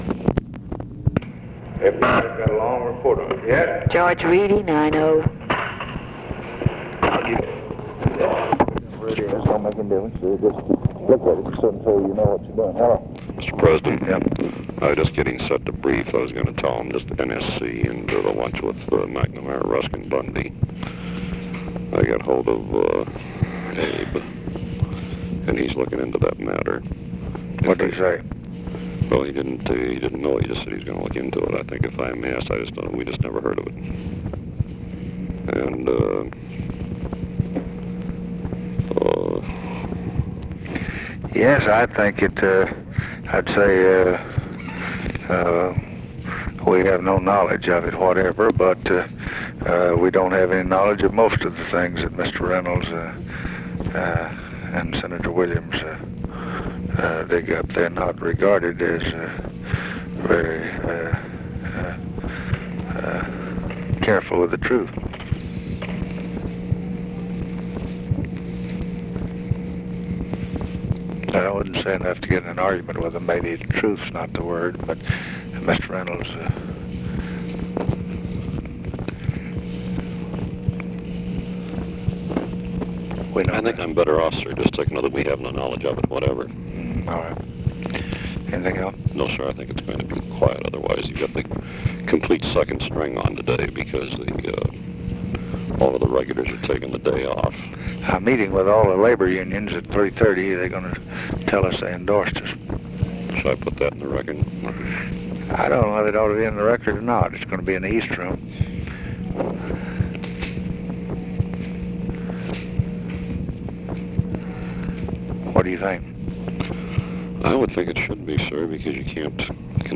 Conversation with GEORGE REEDY and OFFICE CONVERSATION, September 1, 1964
Secret White House Tapes